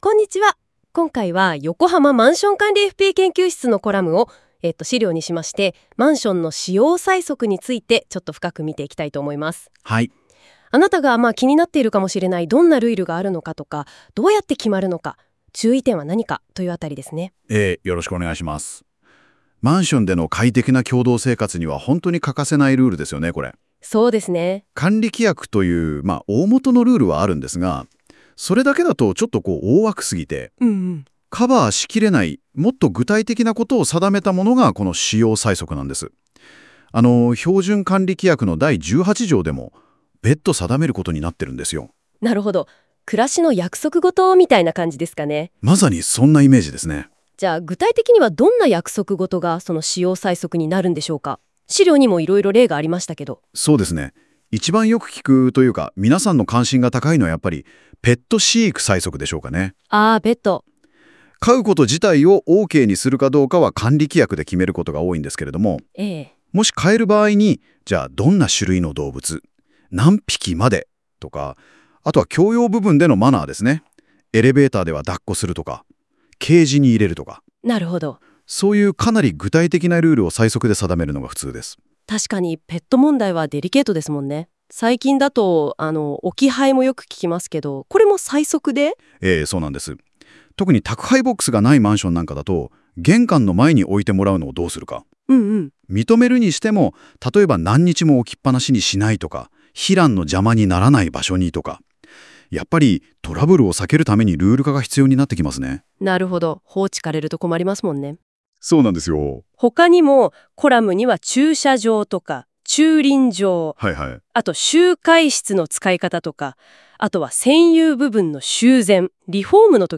新たに、ナレーターによる音声解説を追加しました。使用細則の決議や、使用細則に影響して管理規約を変える必要性もコメントしていますので、コラムを読む上での参考にしてください。